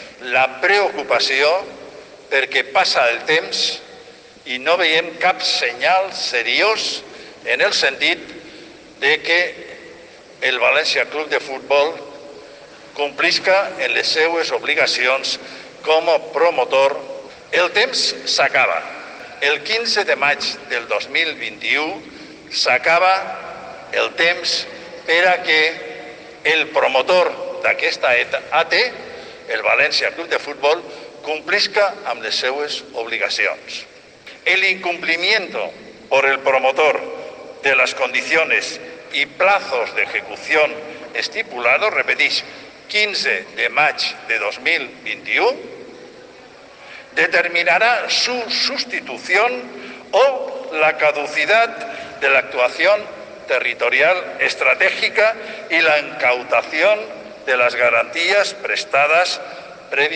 El alcalde de València, Joan Ribó, ha comparecido esta mañana ante los medios de comunicación, y ha advertido a la actual directiva del Valencia CF de que se aproxima el plazo para que haga frente a las obligaciones contraídas respecto a la ejecución del nuevo estadio y el polideportivo de Benicalap.